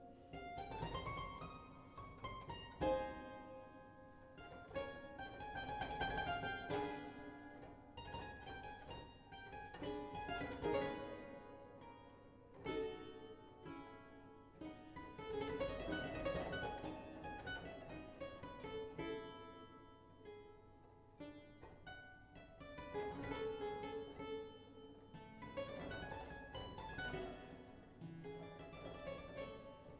Clavichord